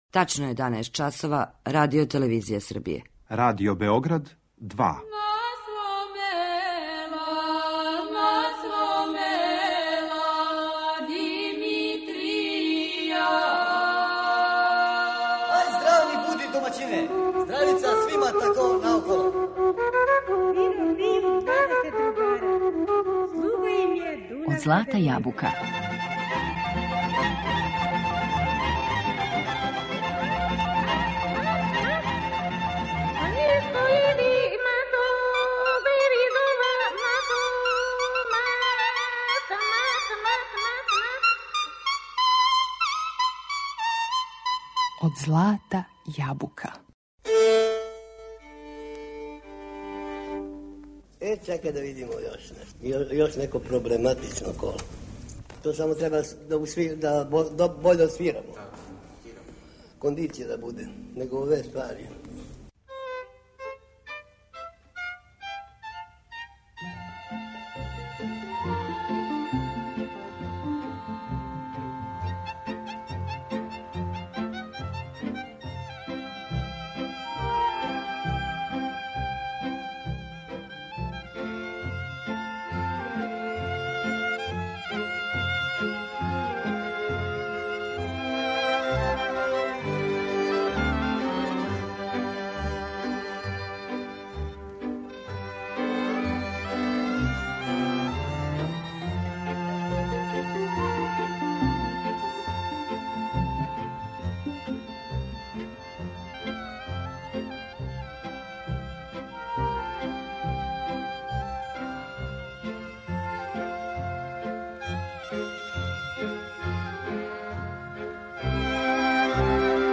Данашњу емисију Од злата јабука, посветили смо Властимиру Павловићу Царевцу, педагогу, виолинисти и шефу оркестра Радио Београда. Слушаћемо снимке овог уметника који се налазе трајно забележени у нашем Тонском Архиву, а потичу из 1957, 1958, па све до 1964. године.